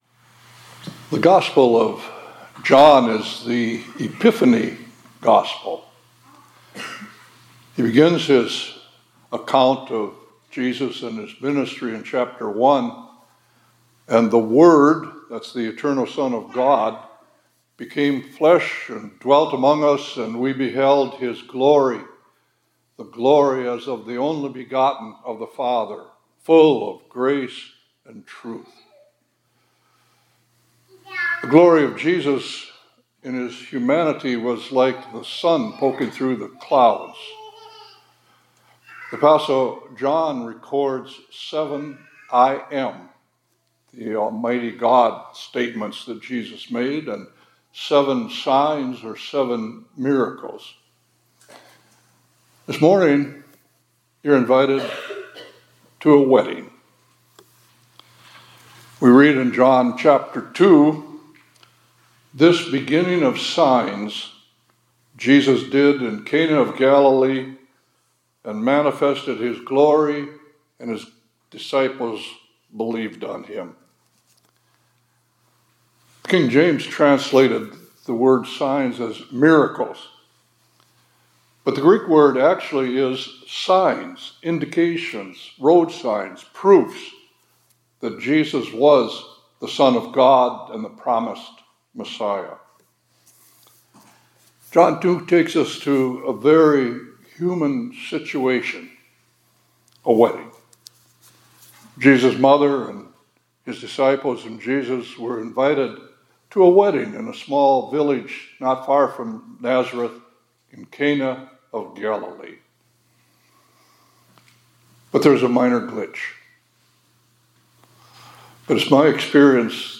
2025-01-23 ILC Chapel — You’re Invited to a Wedding